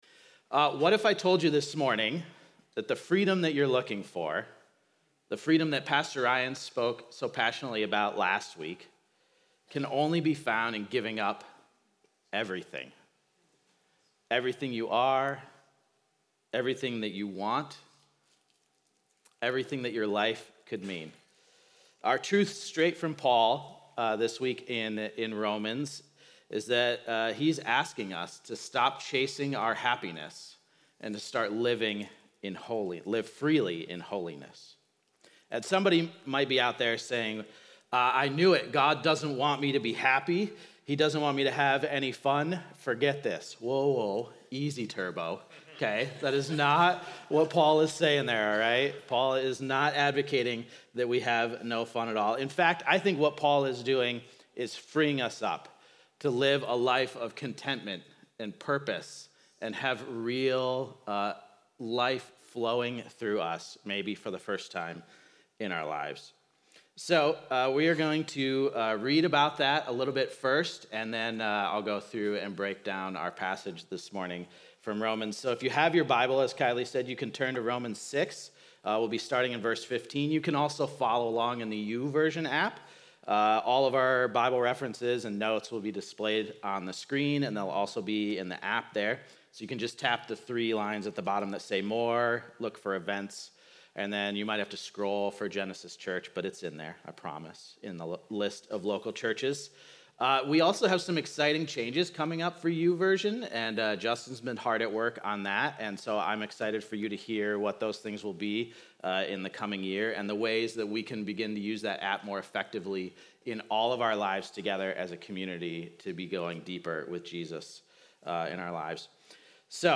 keyboard_arrow_left Sermons / Romans Series Download MP3 Watch Listen Facebook Twitter Copy Link Happiness or Holiness?